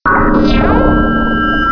P3D-Legacy / P3D / Content / Sounds / Cries / 442.wav